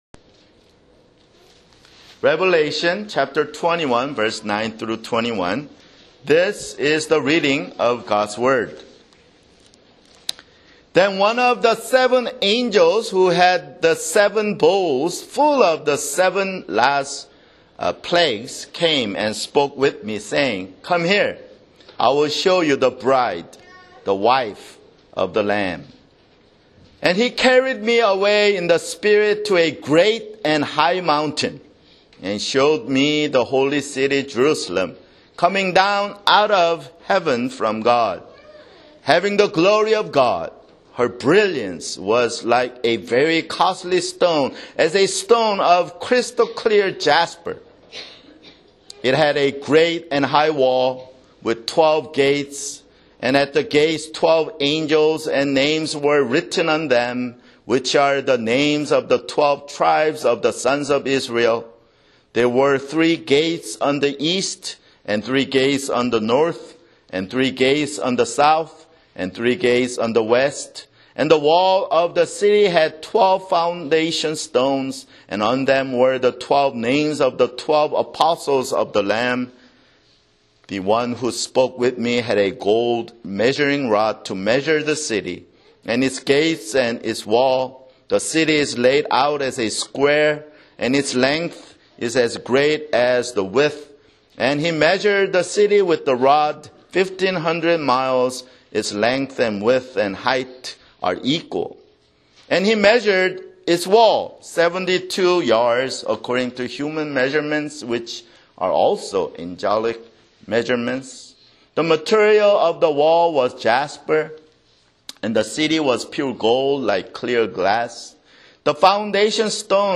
[Sermon] Revelation (87)